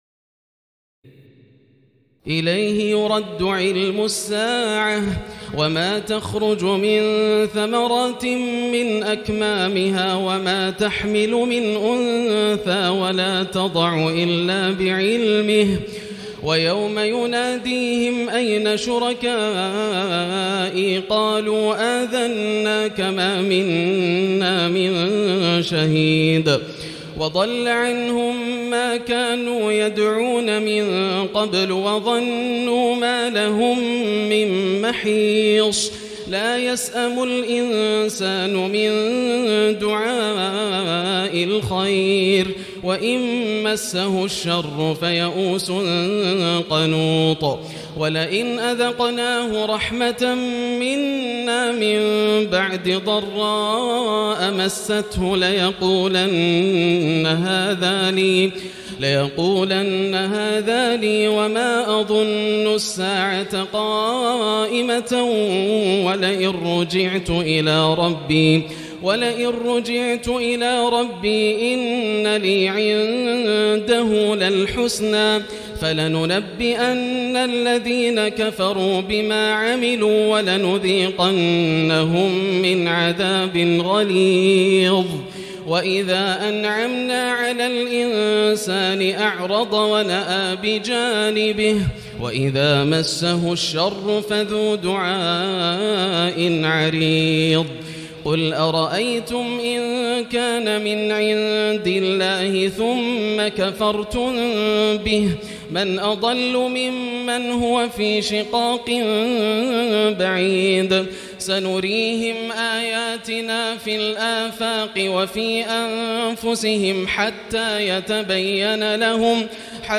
الليلة الرابعة والعشرون - ما تيسر من سور فصلت47-54 و الشورى و الزخرف1-25 > الليالي الكاملة > رمضان 1439هـ > التراويح - تلاوات ياسر الدوسري